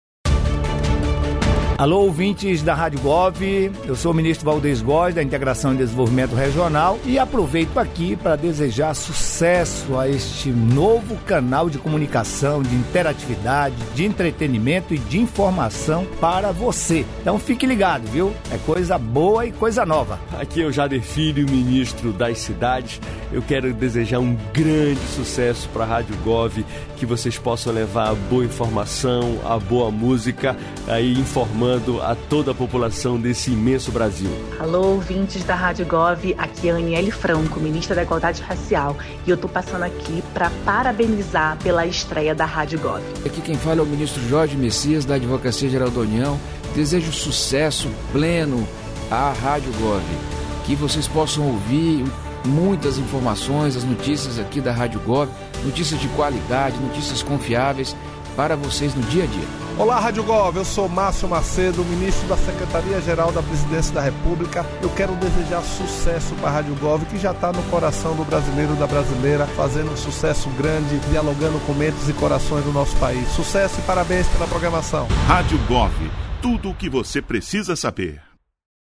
Spots